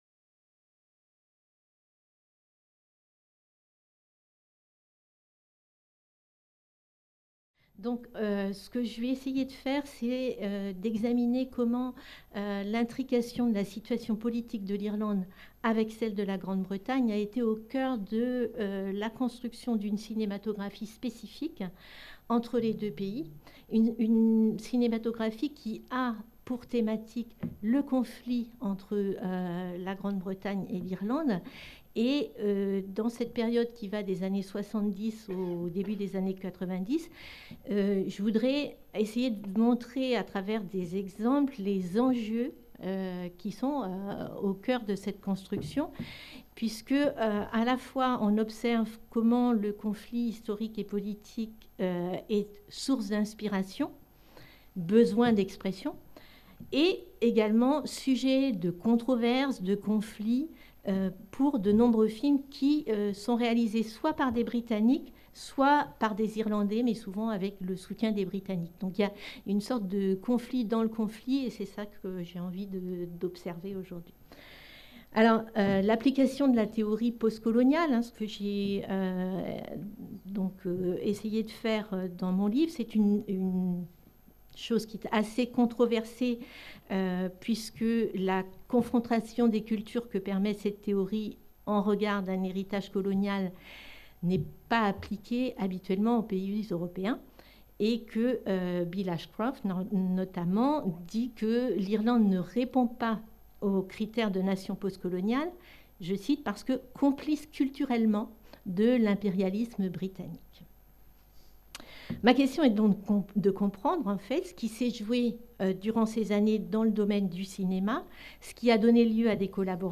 Cette communication a été filmée dans le cadre d'une journée d'étude du LASLAR consacrée au cinéma britannique.